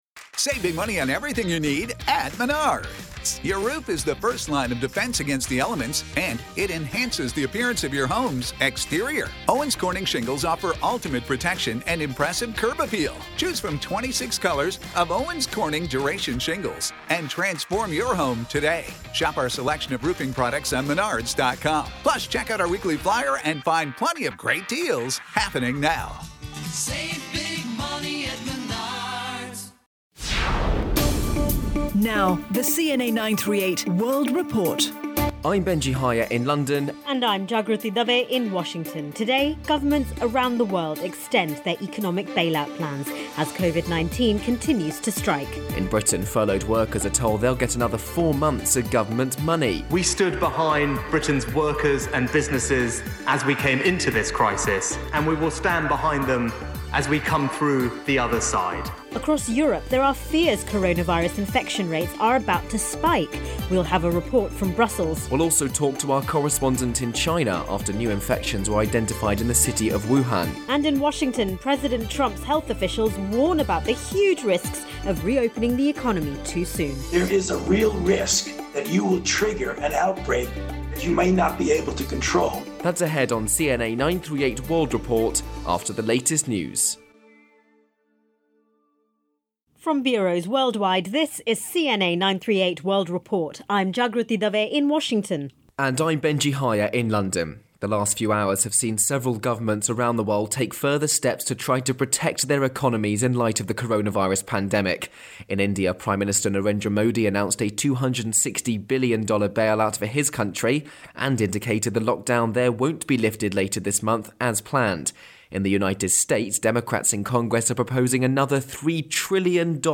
Full edition of CNA 938 World Report, the daily weekday early-morning news programme produced for Singapore's news-talk station by Feature Story News.